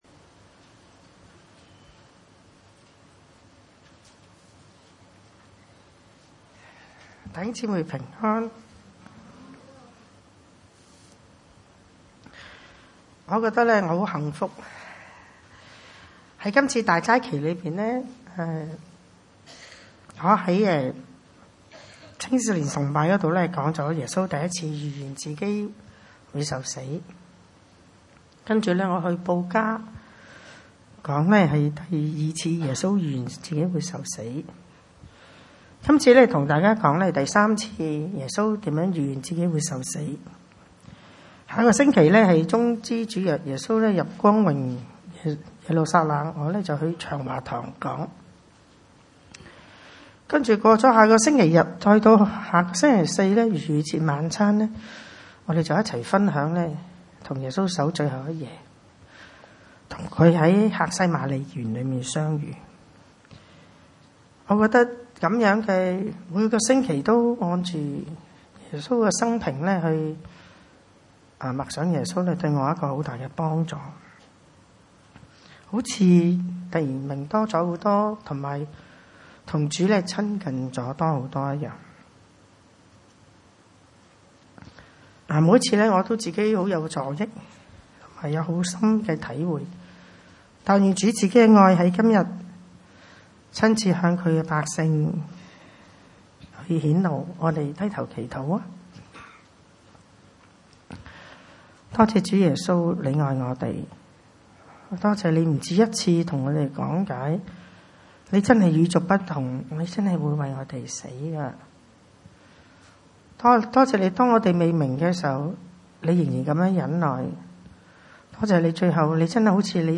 24-26 崇拜類別: 主日午堂崇拜 馬可福音10